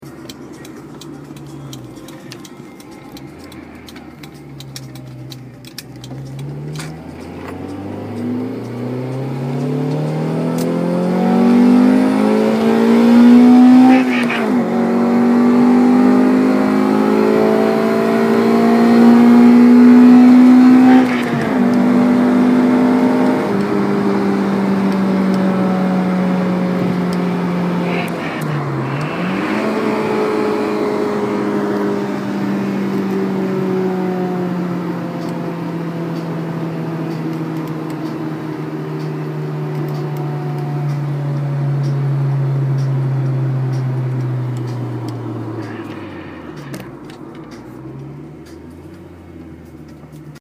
so und als ich vom Waschen wieder in die Garage gefahren bin, habe ich mal eien Soundfile gemacht. ich hoffe ihr könnt damit etwas anfangen:
PS. Das komische Geräusch beim Schalten ist dadurch entstanden das ich das handy in der Hand gehalten habe zum aufnehmen :)
klingt ganz schön gut! :yes: